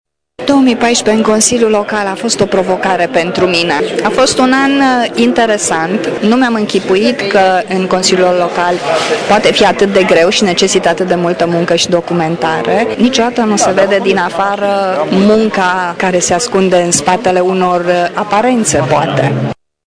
Consilierul PSD, Cristina Someşan, a spus că anul 2014 a fost o provocare şi că munca în Consiliul Local presupune multă documentare: